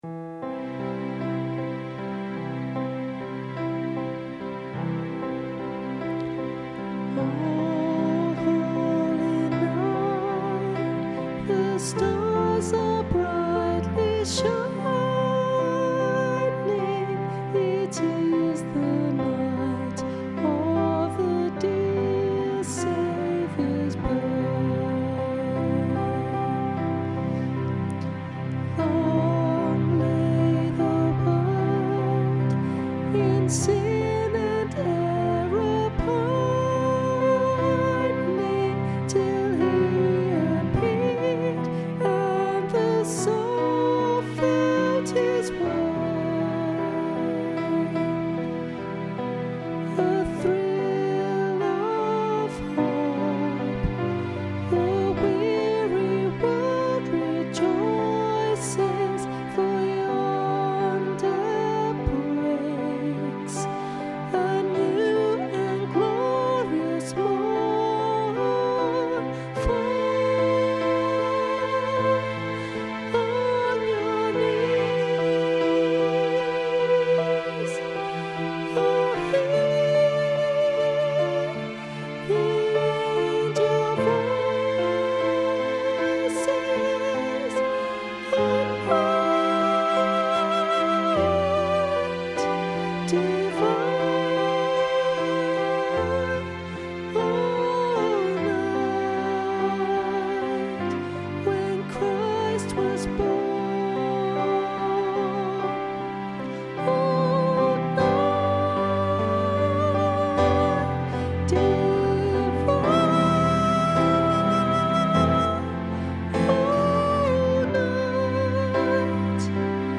O-holy-night-soprano.mp3